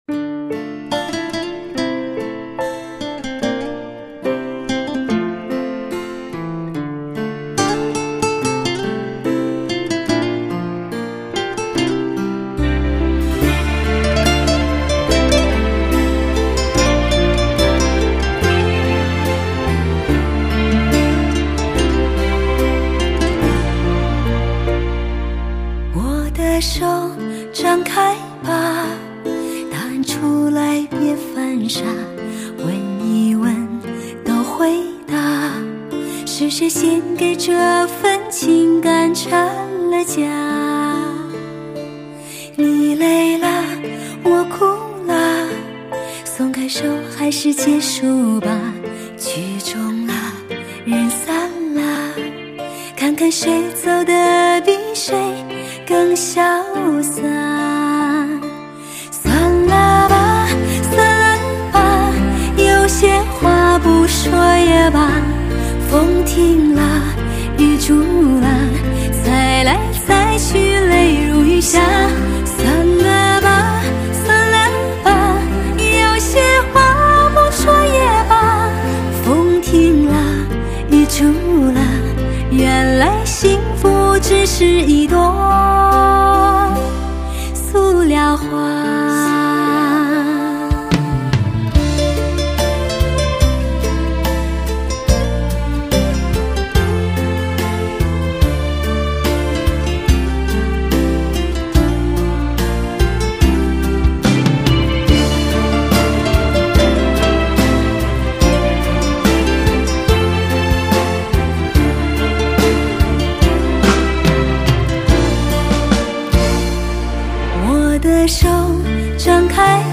类型: 天籁人声
来自草原的天籁之音，拥有草原最具味道的靓美嗓音，她一直在用欢快的心去歌
清脆的嗓音，悠扬的旋律